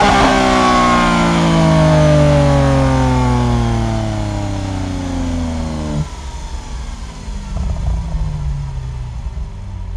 rr3-assets/files/.depot/audio/Vehicles/v12_04/v12_04_decel.wav
v12_04_decel.wav